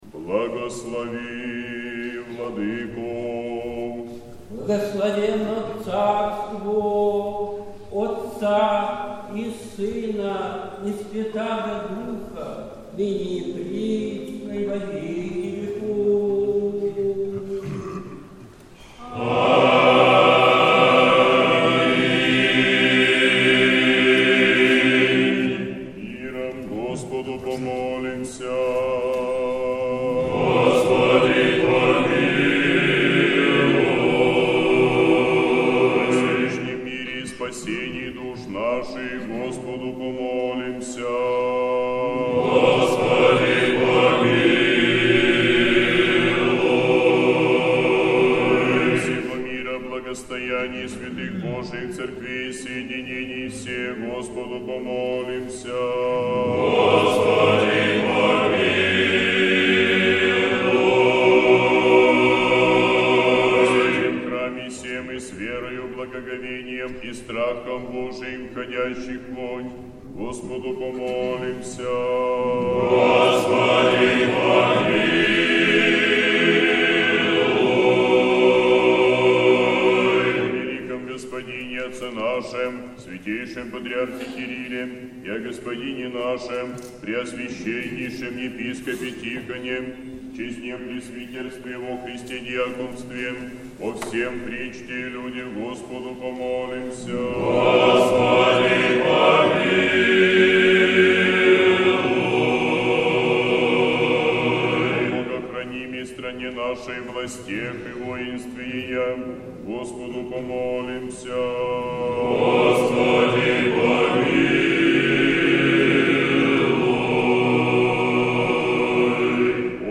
Сретенский монастырь. Божественная литургия. Хор Сретенского монастыря.
Божественная литургия в Сретенском монастыре в Неделю 32-ю по Пятидесятнице, по Рождестве Христовом